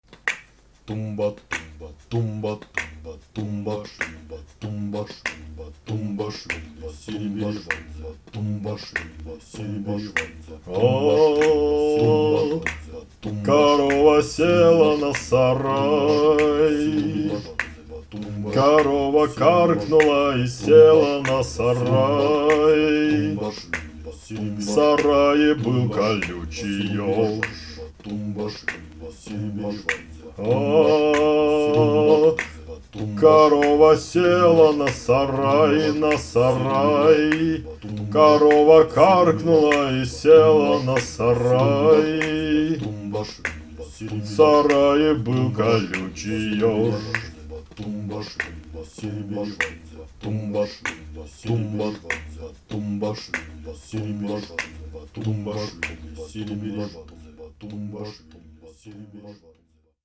Как особо отмечают знатоки, им обязательно должен быть человек, абсолютно лишённый слуха, но с очень громким голосом.